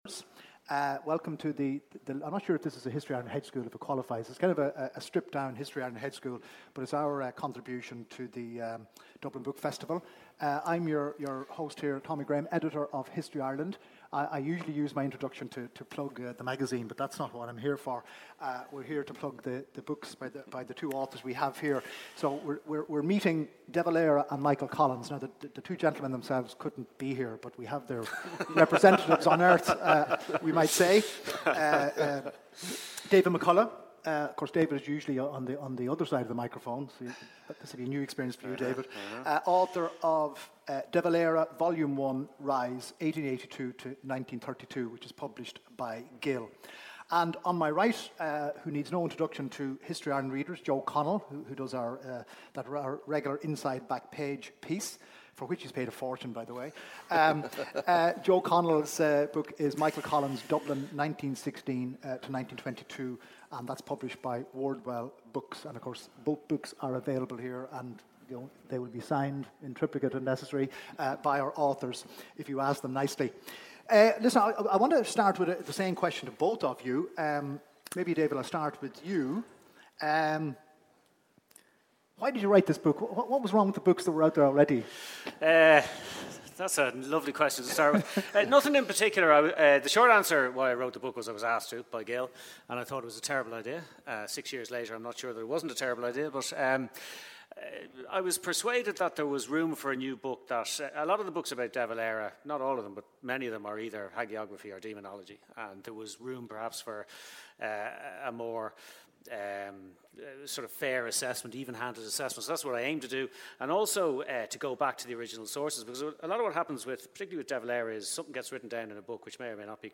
@ Dublin Book Fesitval, RDS Library, Dublin. 2.30pm Sun 5 Nov